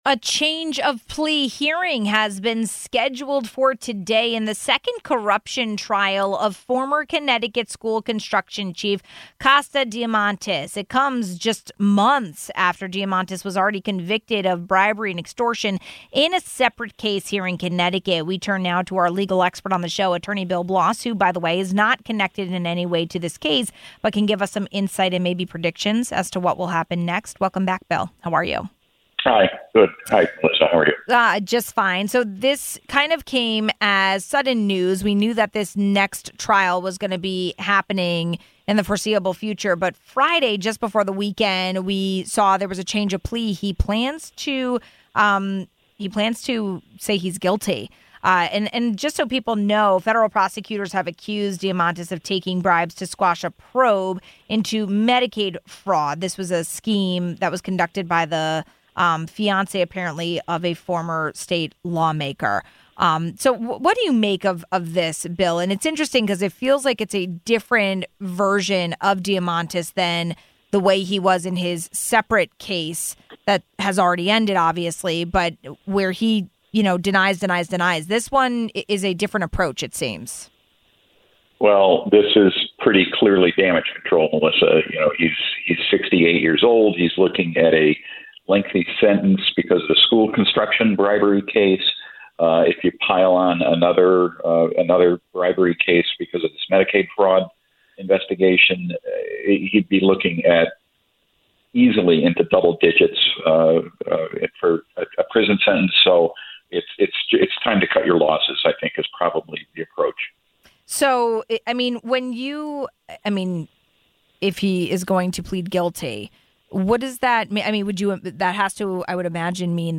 A change of plea hearing has been scheduled in the second corruption trial of former Connecticut school construction chief Konstantinos Diamantis. It comes months after Diamantis was convicted of bribery and extortion in a separate case. We spoke to our legal expert on the show